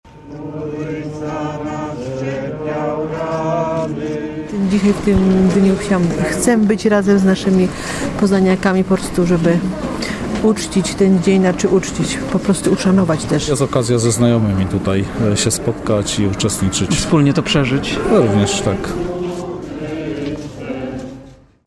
Setki wiernych na Poznańskiej Drodze Krzyżowej